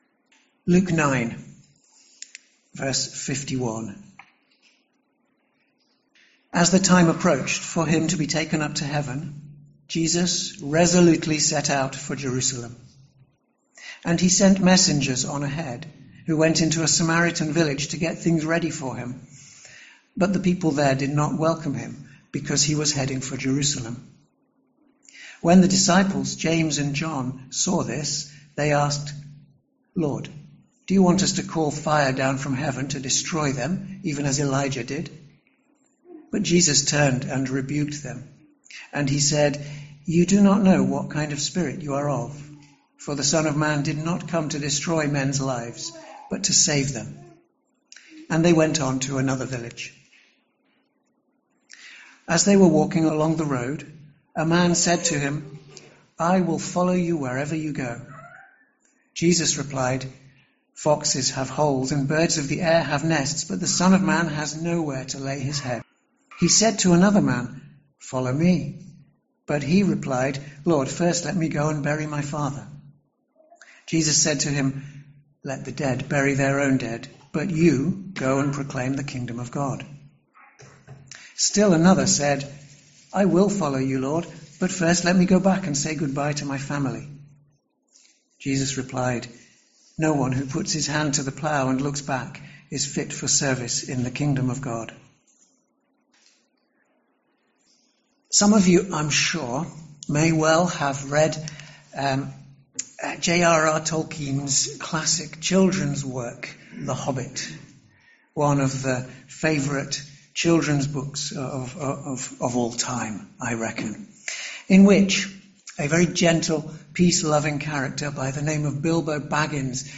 Sermons - Swanfield Chapel
Swanfield Chapel is an Evangelical Church in Chichester, West Sussex, England.